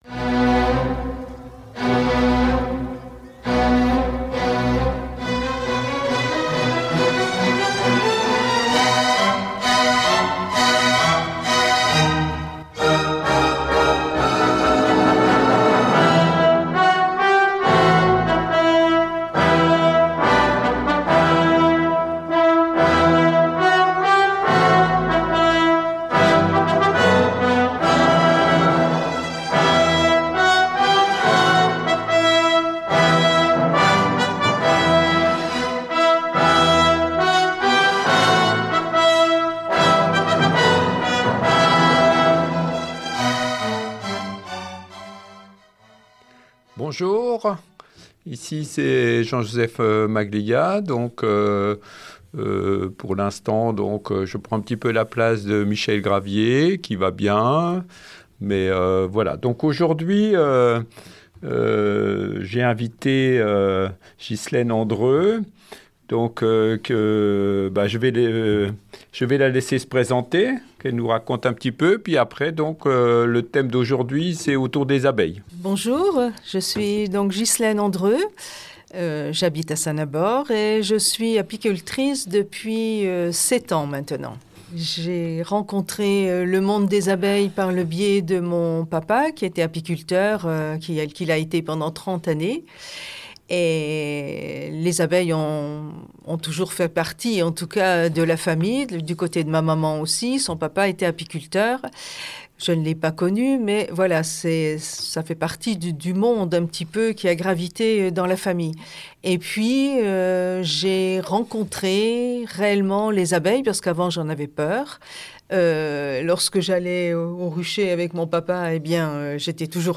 Un échange passionné et passionnant, qui rappelle que protéger les abeilles, c’est aussi protéger la vie elle-même.